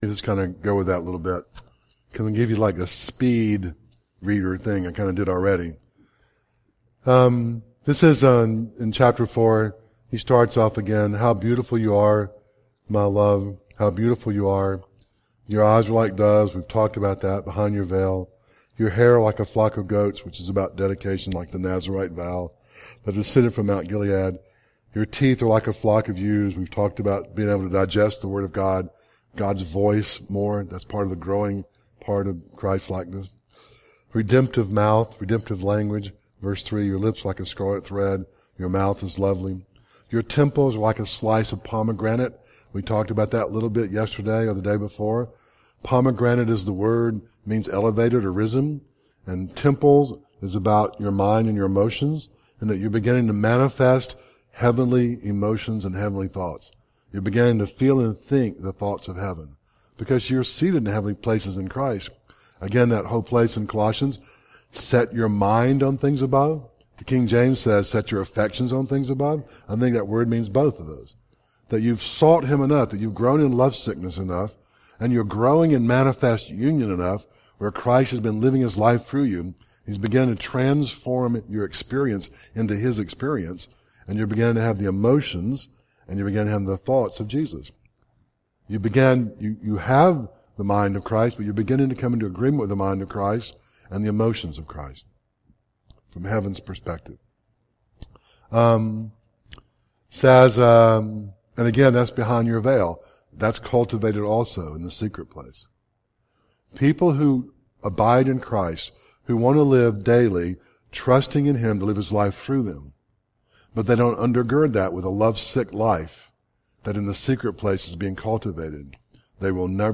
Song of Solomon Service Type: Conference